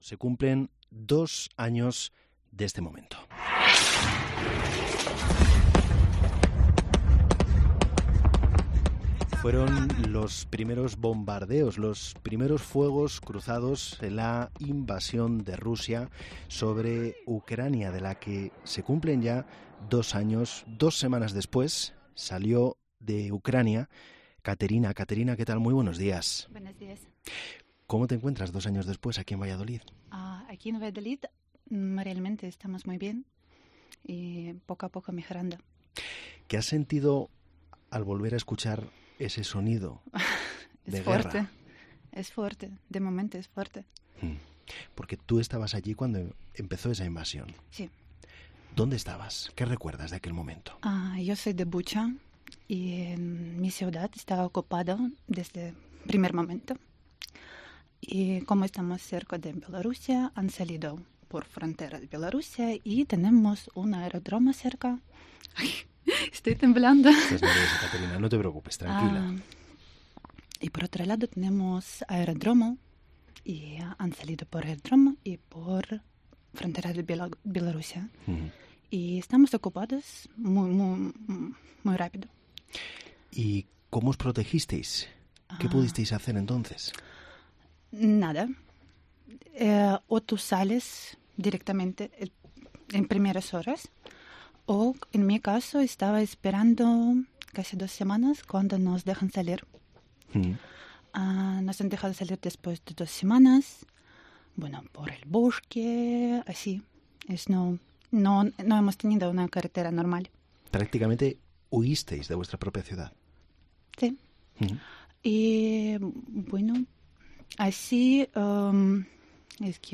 Refugiada ucraniana, en COPE Valladolid: "Cuando empezó la guerra pensé que sería dos o tres días"